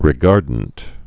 (rĭ-gärdnt)